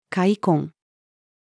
塊魂-female.mp3